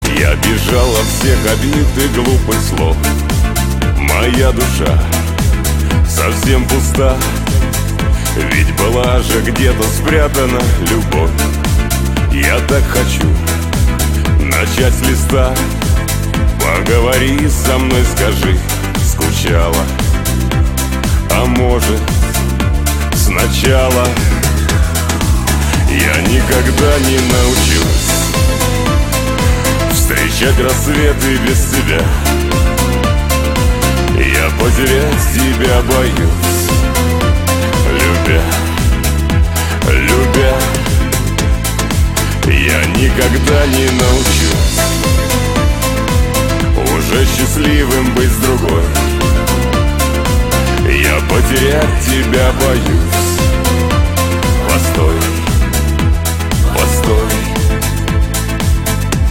• Качество: 256, Stereo
шансон
эстрадные
грубый голос